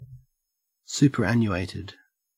Ääntäminen
IPA : /ˌsupɚˈænjuˌeɪtɪd/